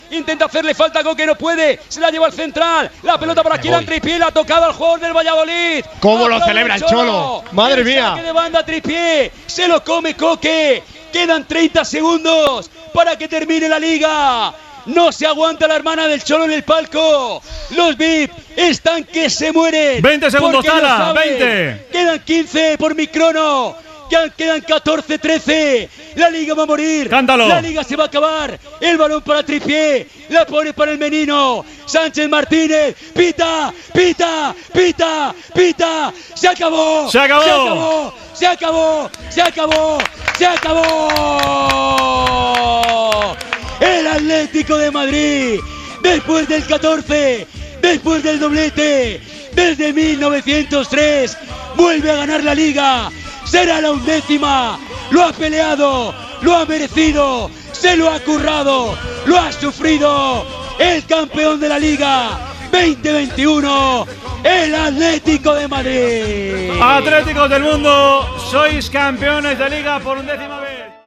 Narració del final del partit de la lliga de futbol masculí entre el Valladolid i l'Atlético de Madrid. L'equip matalasser es proclama campió de lliga
Esportiu